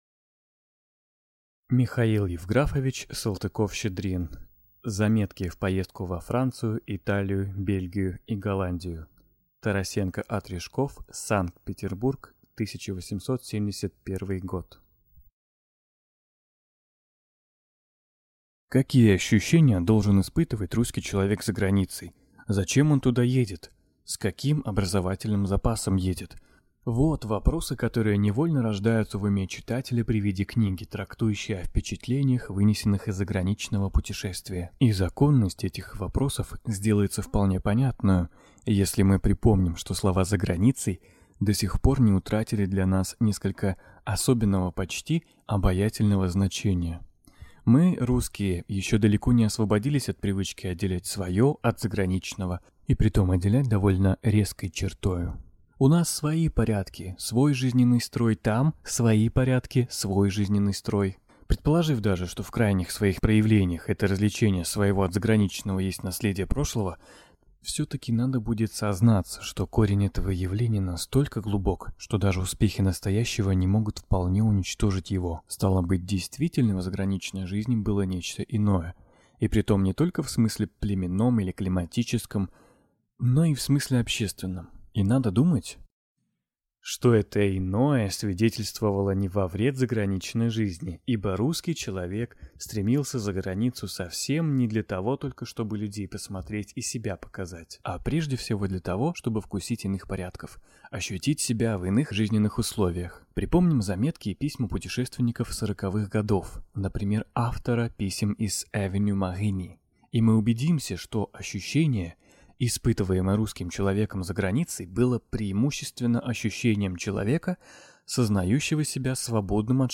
Аудиокнига Заметки в поездку во Францию, С. Италию, Бельгию и Голландию.